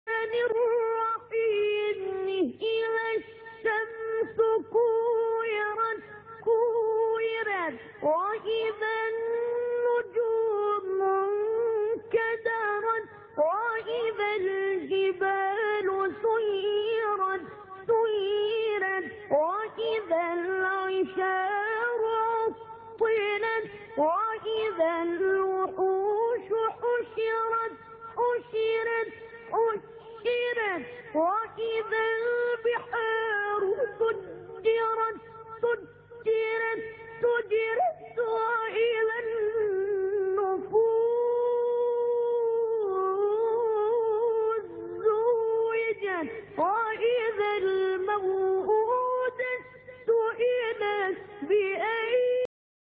دوست عزیز از ترتیل خلیل الحصری شروع کن